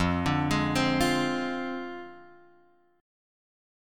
F Minor 6th
Fm6 chord {1 x 0 1 1 1} chord